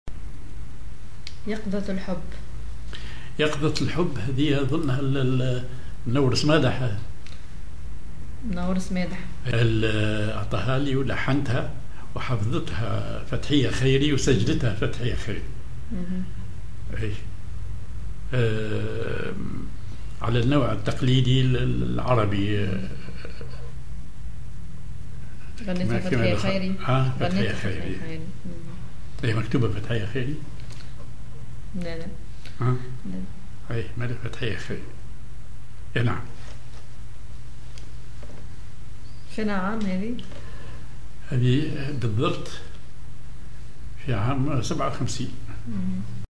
Maqam ar راست
Rhythm ar الوحدة
genre أغنية